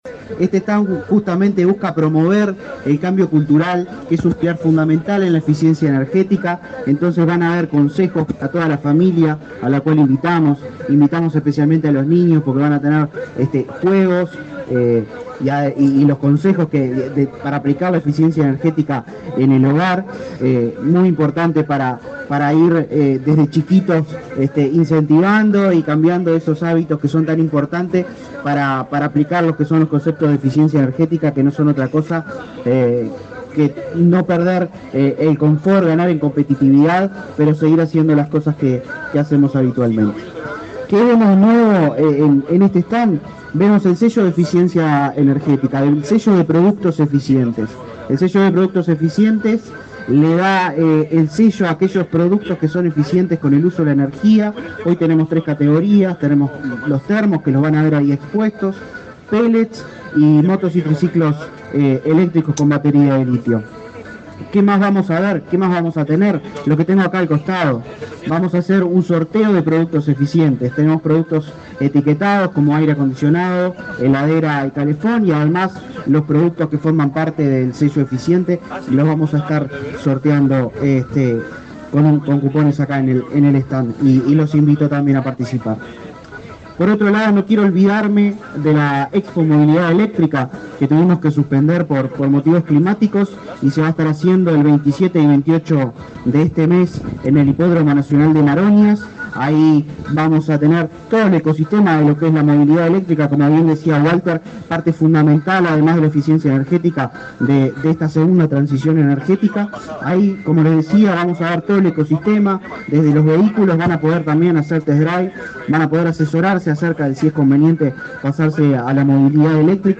Palabras de autoridades del Ministerio de Industria
El director nacional de Energía, Christian Nieves, y el ministro interino de Industria, Walter Verri, participaron, este viernes 6, en la inauguración